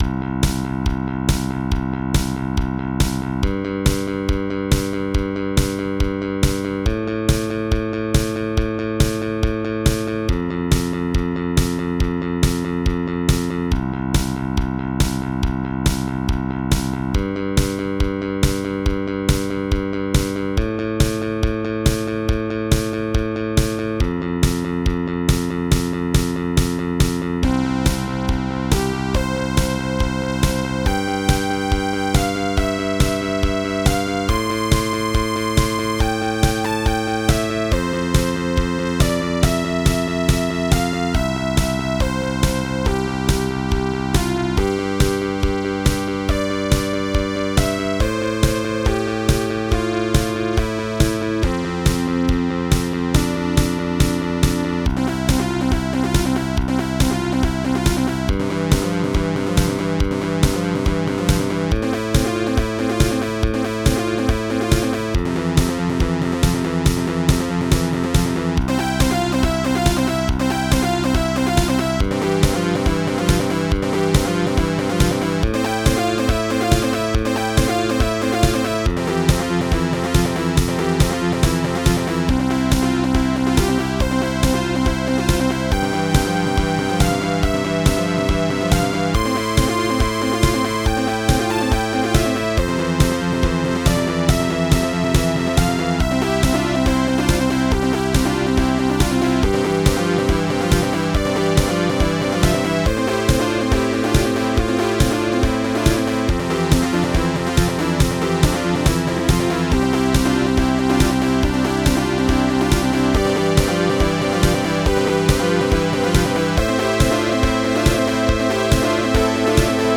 A MIDI remix
It's an uptempo song, but the melody has a subtly somber tone to it.